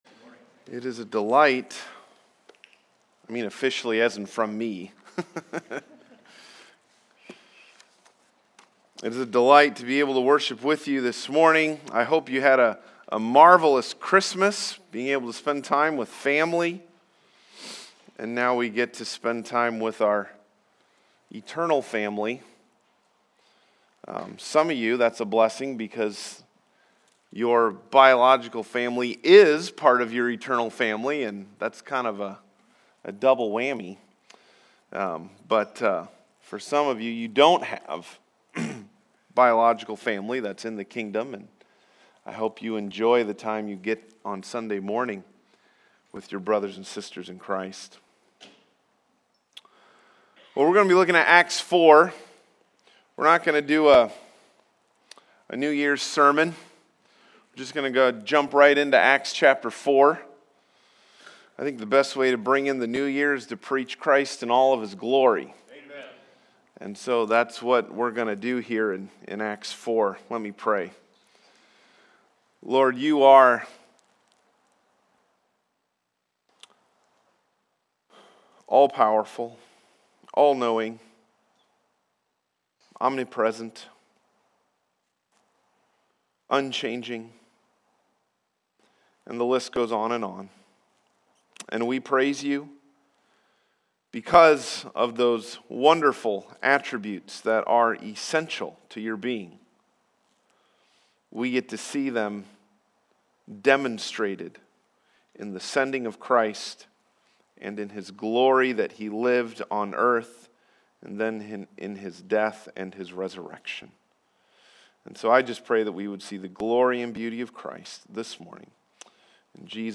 Preacher
Passage: Acts 4:1-22 Service Type: Sunday Morning « Christmas Saved Suffering and Boldness in the Early Church